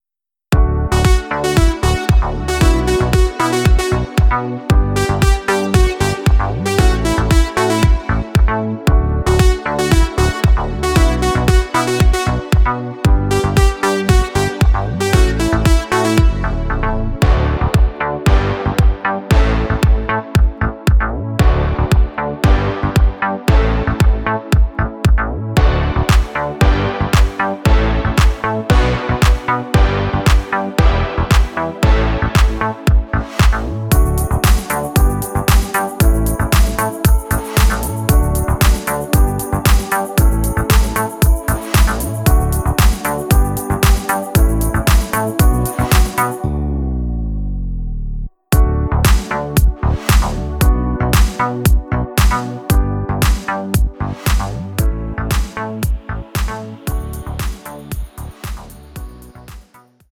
Party Mischung